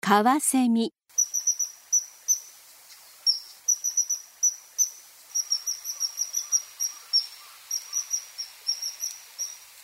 カワセミ
【鳴き声】金属が擦れたような高く細い音で、「ピッ」「チーッ」「ツゥピーィ」などと鳴く。
カワセミの鳴き声（音楽：164KB）